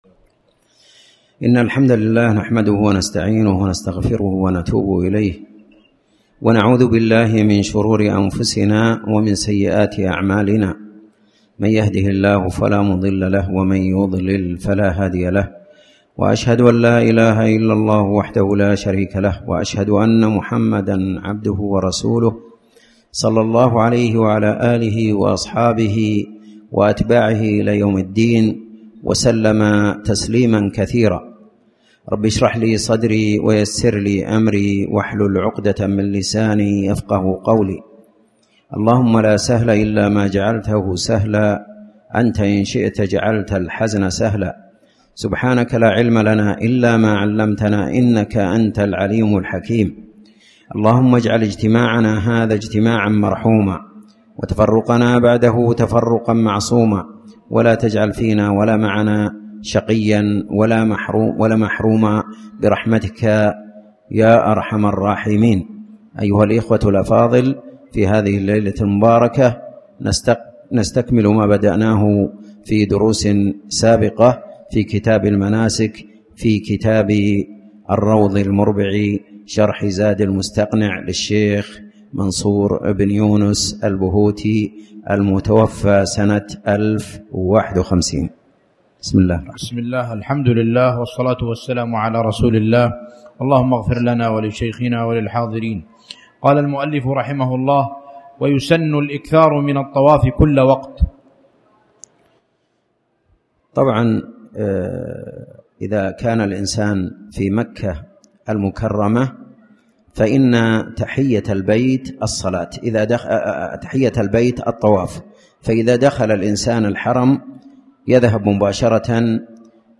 تاريخ النشر ٢٧ محرم ١٤٣٩ هـ المكان: المسجد الحرام الشيخ